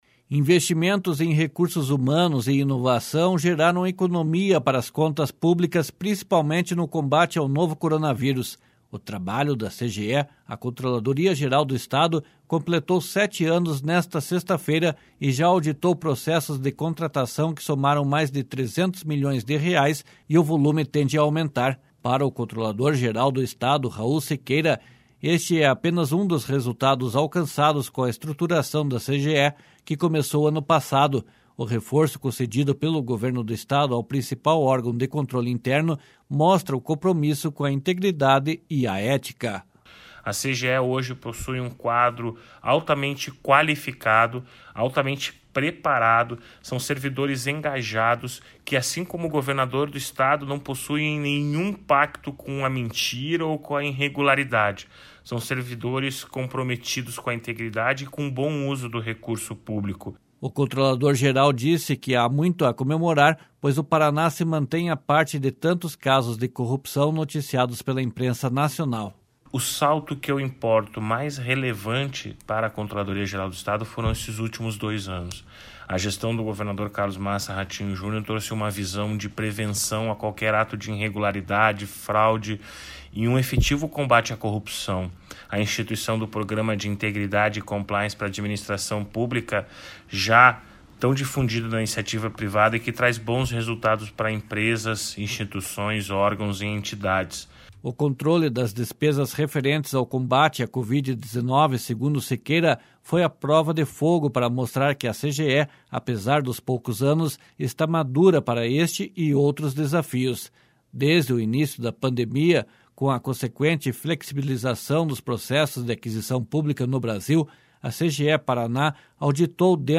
//SONORA RAUL SIQUEIRA//